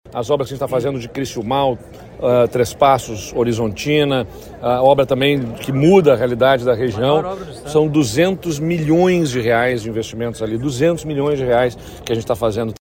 Ele comentou sobre a importância da obra. ( Abaixo, áudio de Eduardo Leite).